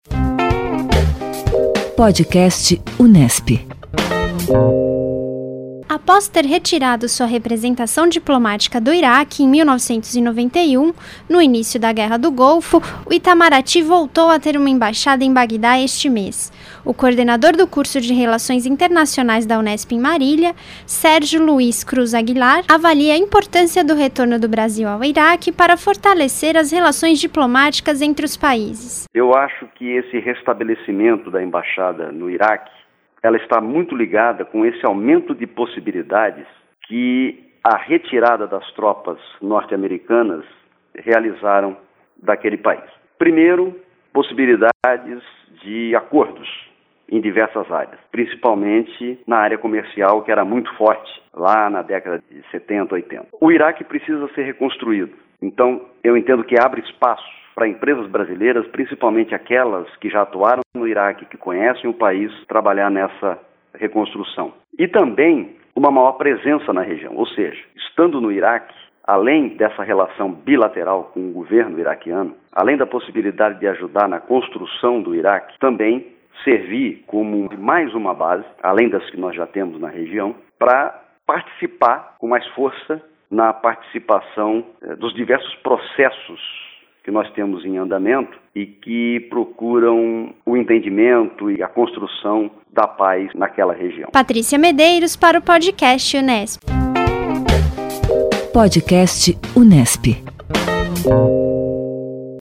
O Podcast Unesp / Assessoria de Comunicação e Imprensa da Reitoria da Unesp traz entrevistas com professores, pesquisadores e alunos sobre pautas cotidianas da mídia brasileira, internacional e informações geradas na Universidade.